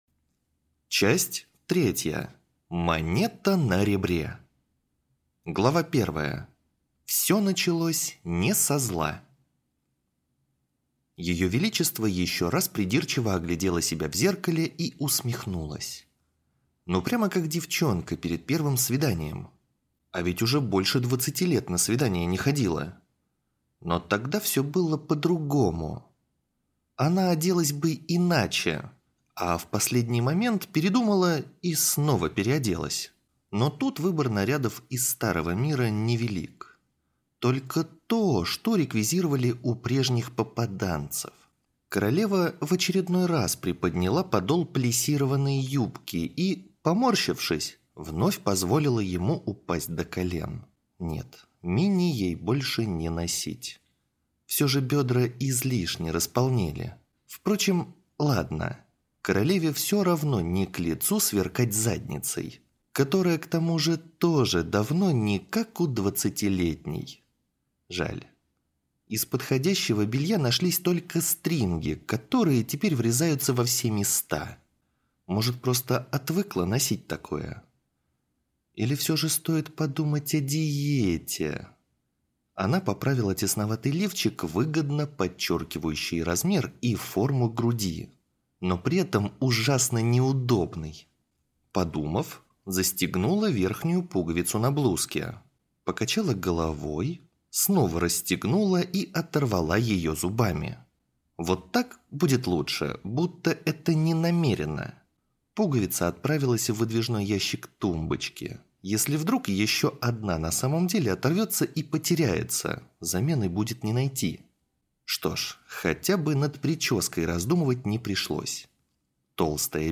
Aудиокнига Не место для героев.